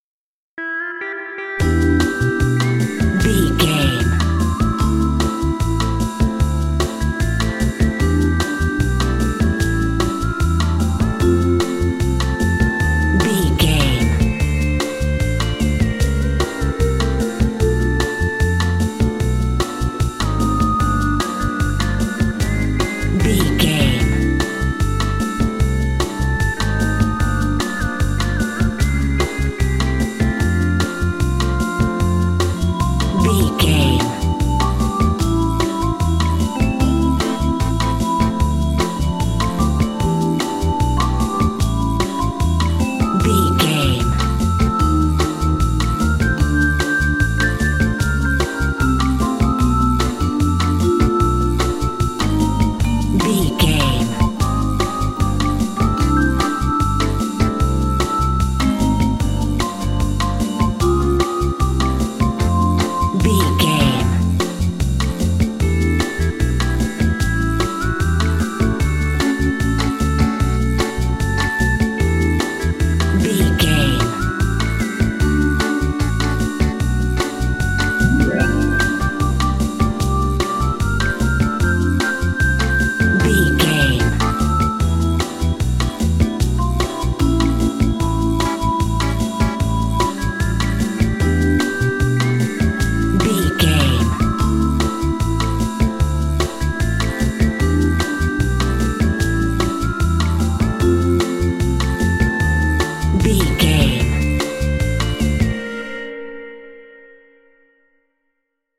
Ionian/Major
fun
happy
light
uplifting
bouncy
joyful
bass guitar
drums
synthesiser
percussion
bossa
latin jazz